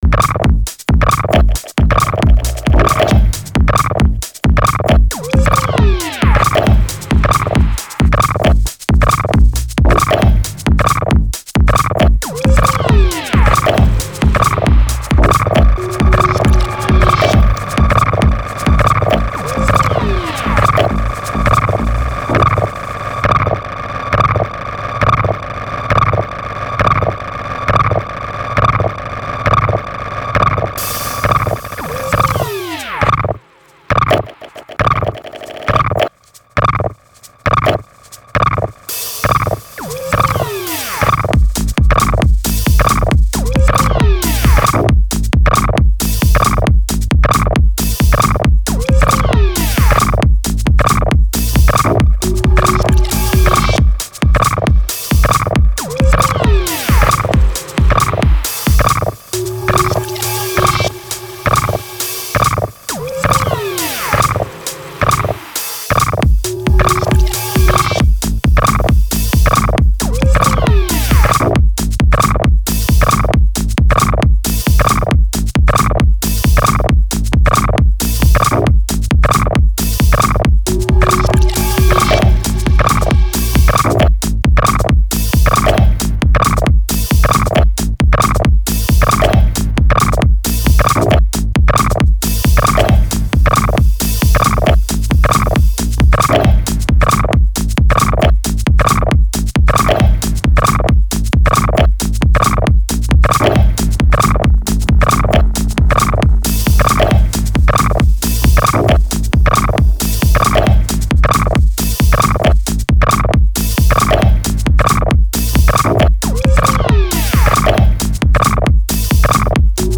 4 tracks – weird, straight and powerfull as usual.
Style: Techno